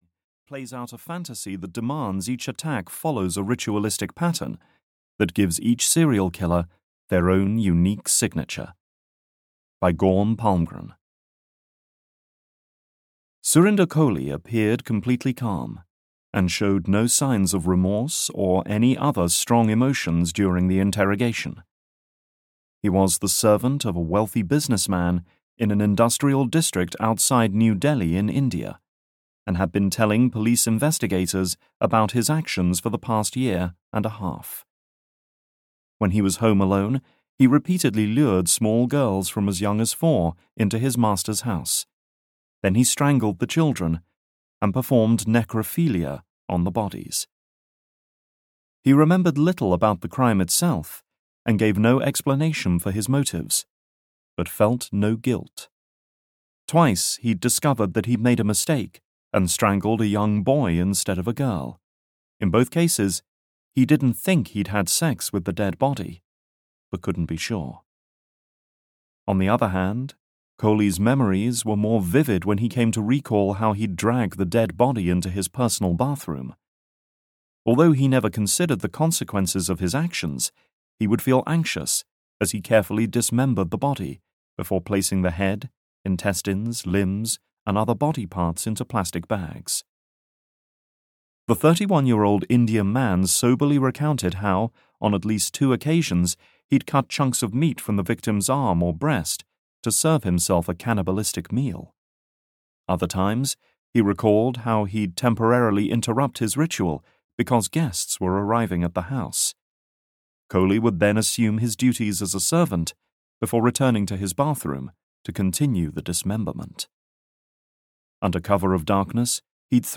Audio knihaIce Cold Killers - Addicted to Death (EN)
Ukázka z knihy